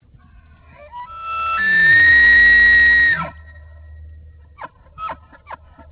The Elk of Rocky Mountain National Park
The bull elk bugle to warn other bulls to stay away.
Click here to listen to an elk bugling.
elk.wav